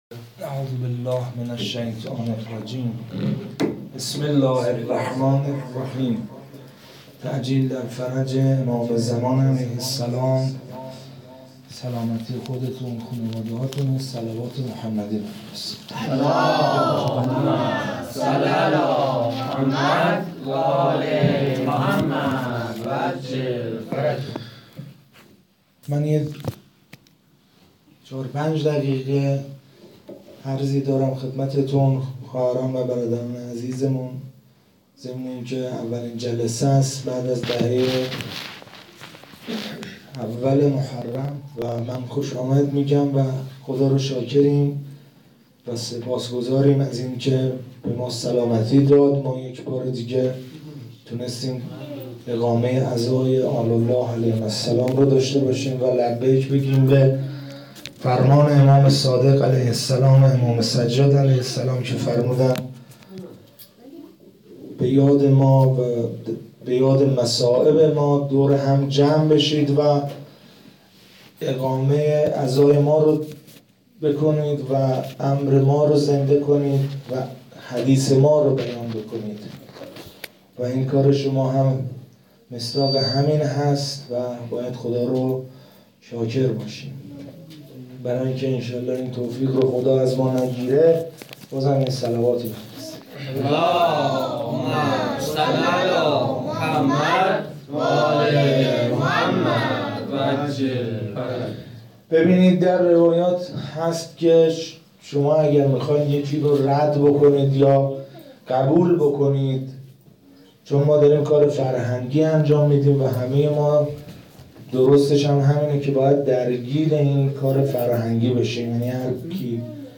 سخنرانی
جلسه هفتگی95/7/28